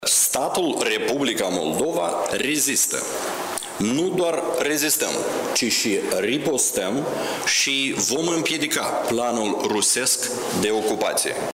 „Presiunea devine tot mai mare, iar probele în ceea ce privește acțiunile subversive ale Rusiei sunt tot mai numeroase”, a spus premierul Dorin Recean într-o conferință de presă.